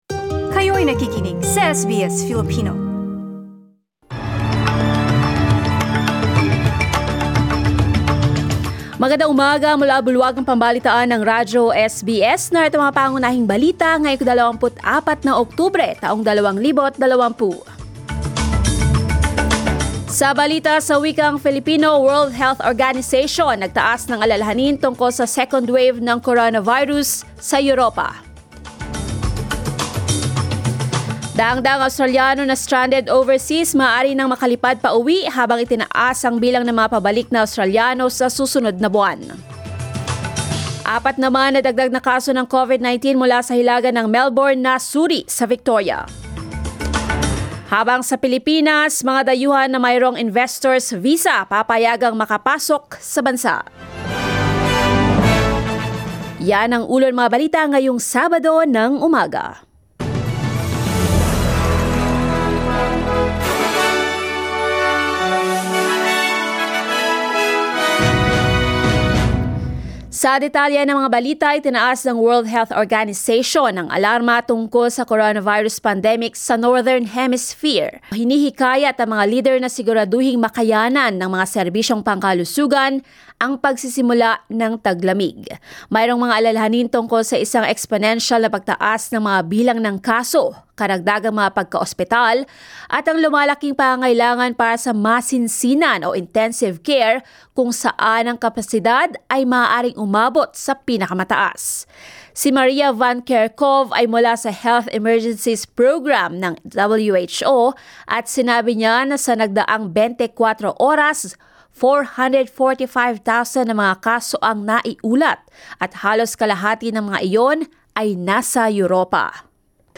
SBS News in Filipino, Saturday 24 October